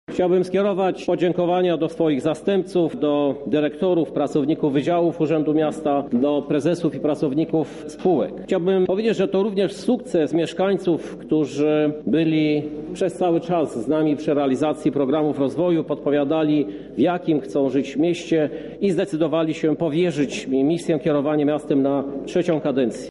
Okazją do tego była pierwsza sesja Rady Miasta tej kadencji.